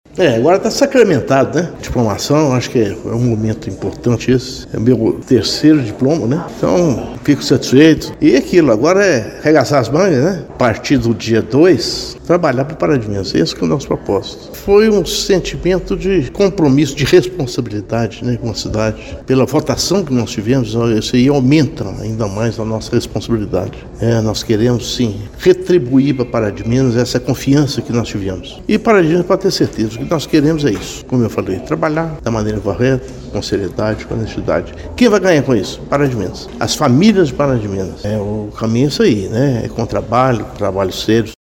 A cerimônia aconteceu na sede do Juizado Especial e foi presidida pela juíza eleitoral, Doutora Gabriela Andrade de Alencar Ramos.